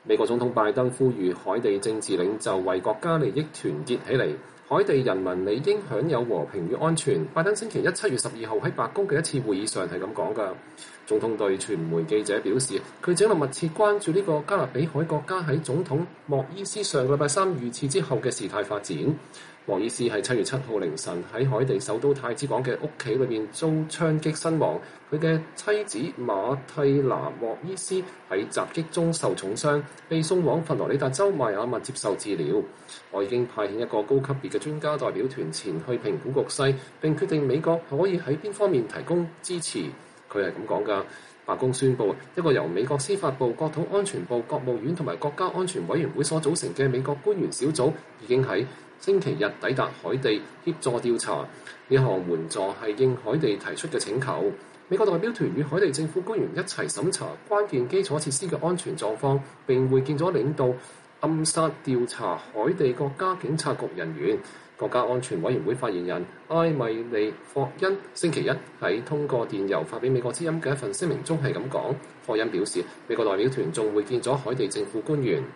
海地人民理應享有和平與安全，” 拜登週一（7月12日）在白宮的一次會議上說。